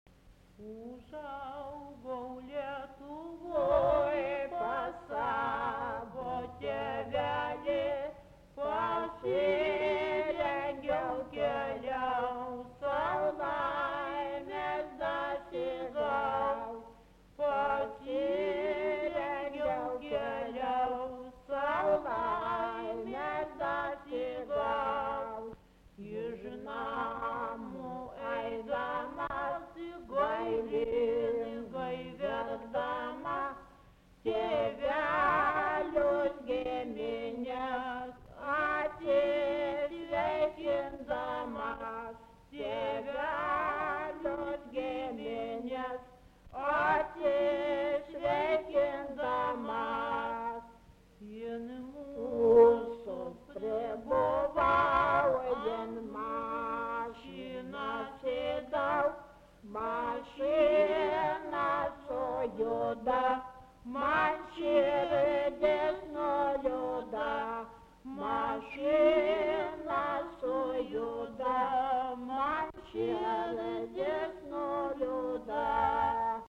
Tipas daina Erdvinė aprėptis Slabada (Kaišiadorys)
Atlikimo pubūdis vokalinis
dainuoja dvi dainininkės dviem balsais